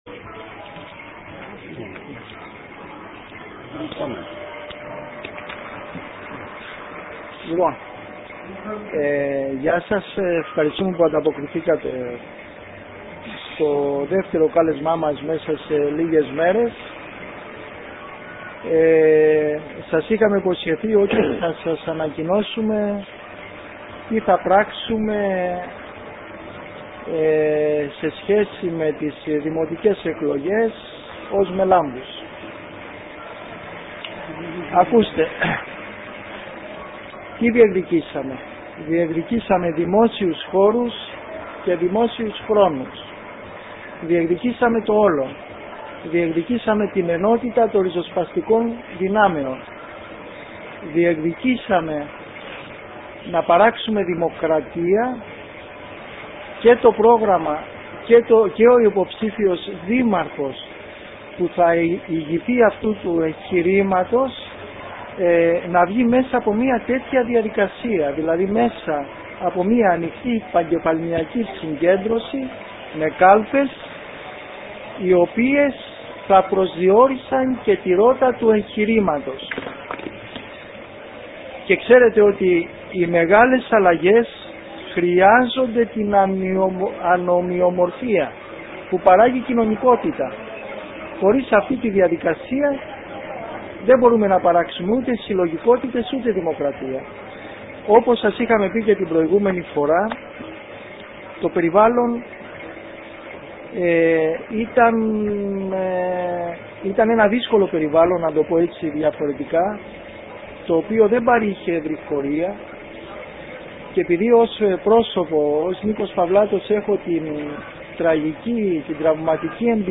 ΣΥΝΕΝΤΕΥΞΗ ΜΕΛΑΜΠΟΥΣ
ΣΥΝΕΝΤΕΥΞΗ-ΜΕΛΑΜΠΟΥΣ.mp3